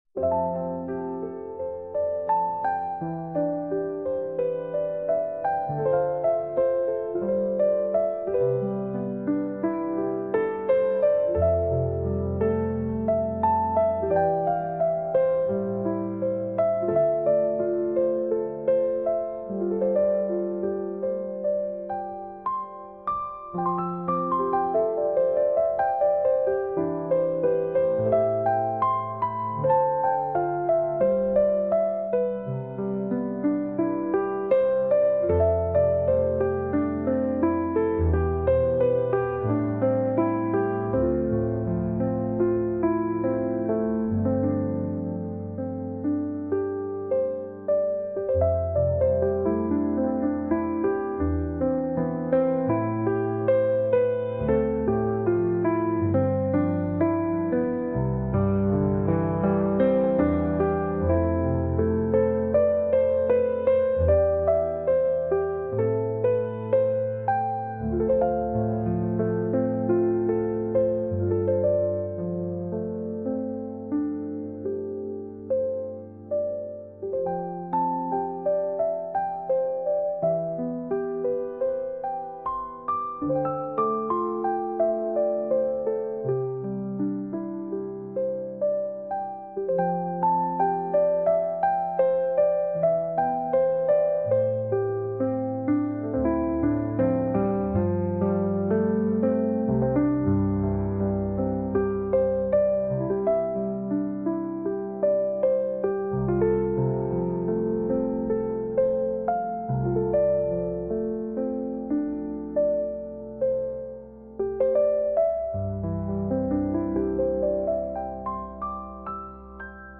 สัมผัสความสงบจากธรรมชาติด้วยเพลงผ่อนคลายสำหรับการทำสมาธิ ช่วยให้นอนหลับสบาย